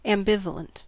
Ääntäminen
IPA : /æmˈbɪv.ə.lənt/